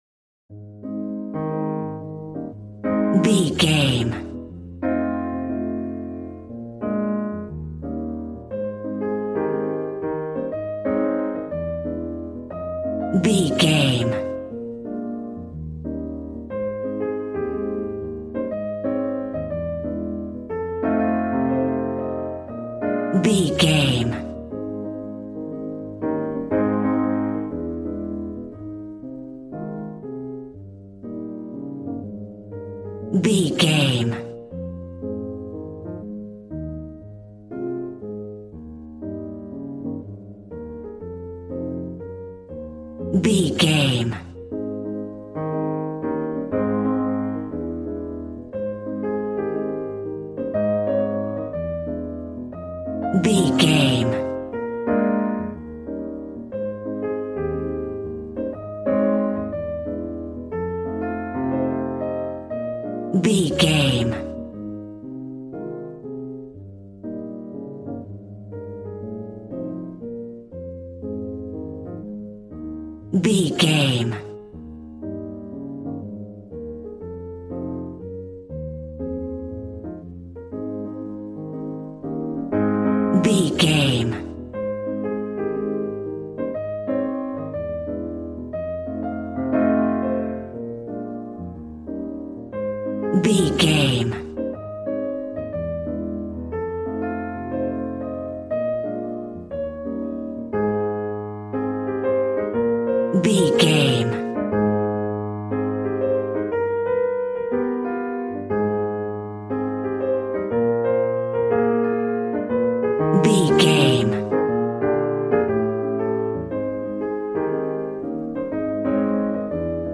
Smooth jazz piano mixed with jazz bass and cool jazz drums.,
Ionian/Major
A♭
piano
drums